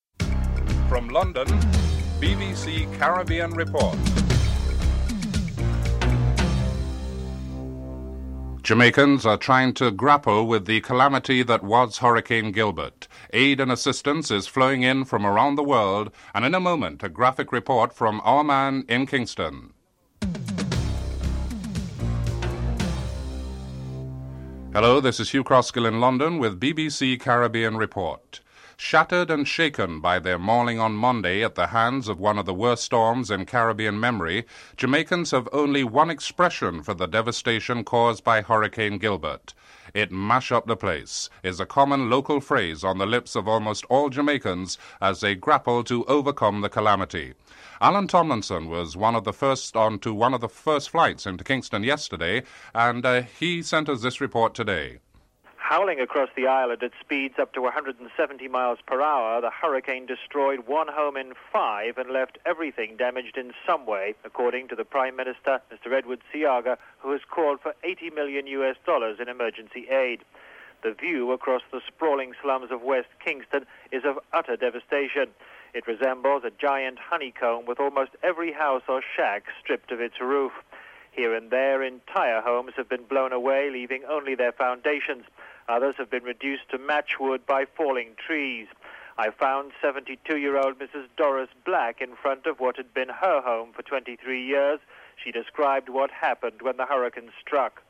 The report focuses on the devastation caused by Hurricane Gilbert in Jamaica. Interviews were conducted with persons who suffered from the disaster.
9. Interview with Mervyn Dymally on regional criticisms of U.S. efforts at tackling the drug trade from the Caribbean and the modest successes of the CBI (11:31-15:08)